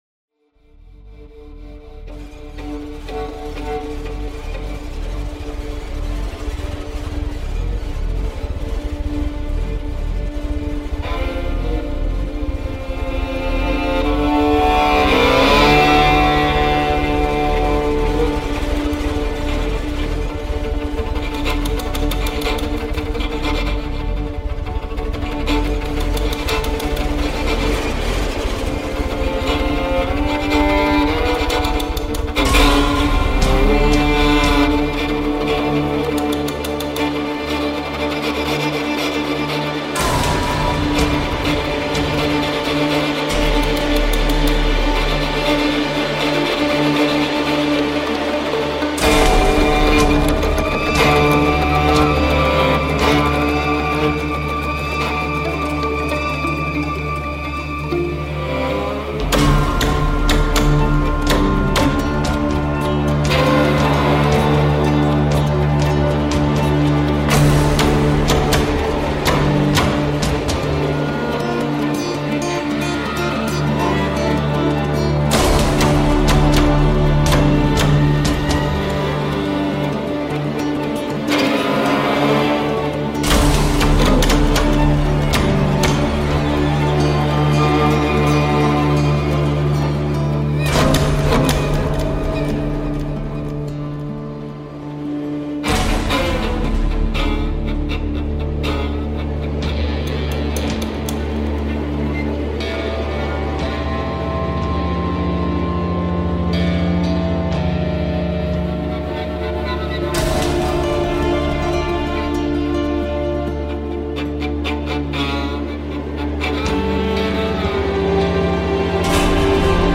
Western track for first-person shooter, action and RPG.